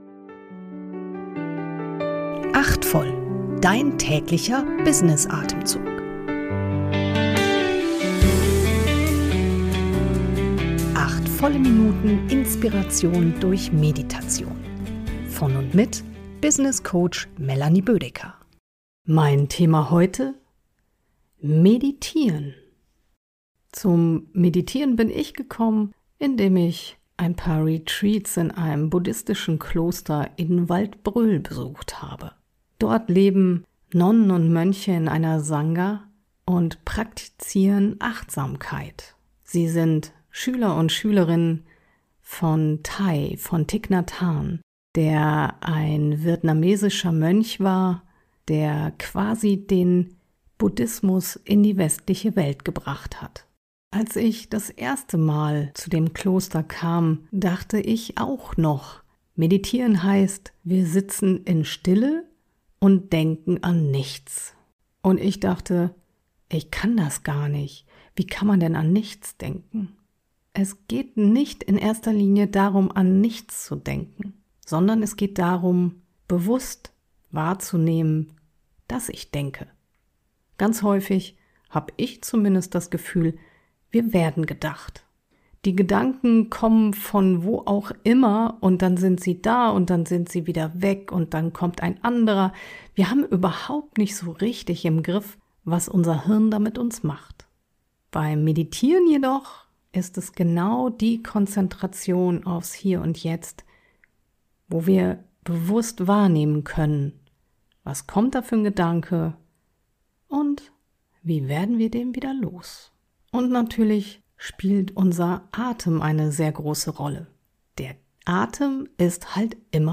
Erfrischung durch eine geleitete Kurz-Meditation.